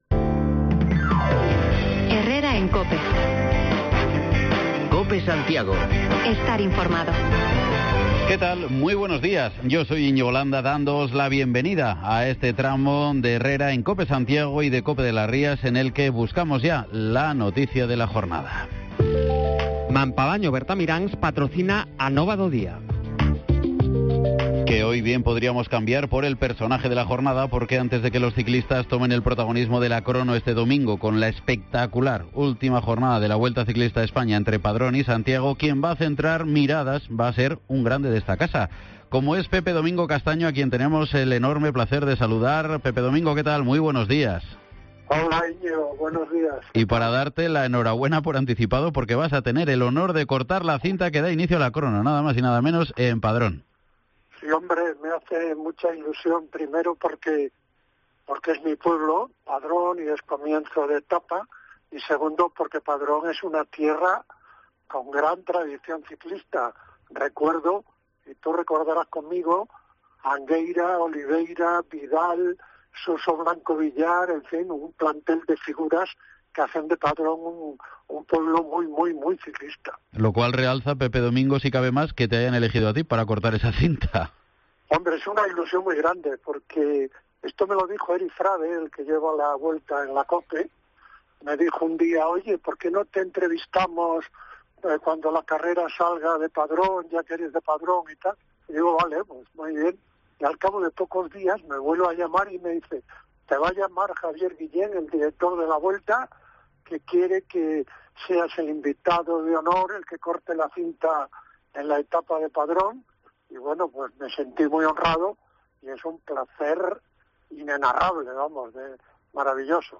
Escucha la entrevista completa en COPE Santiago con Pepe Domingo Castaño